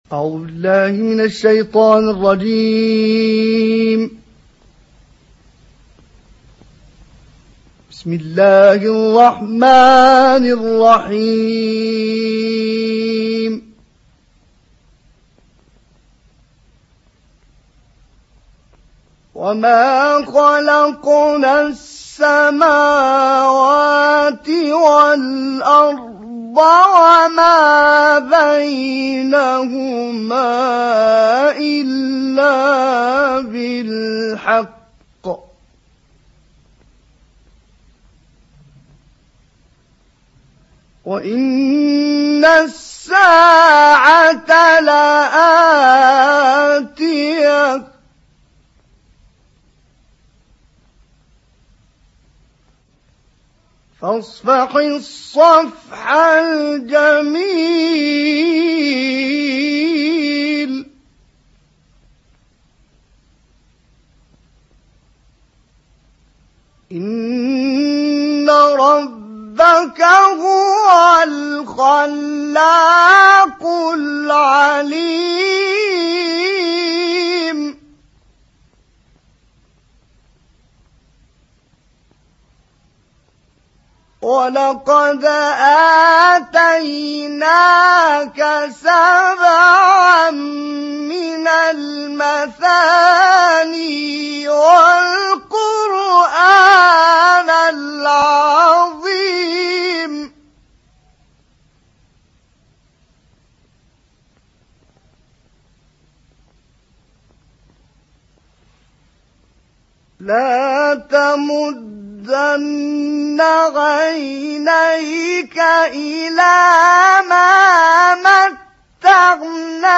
باقة من التلاوات الخاشعة والنادرة للقارئ محمد عبد العزيز حصان 5